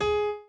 b_pianochord_v100l4o5gp.ogg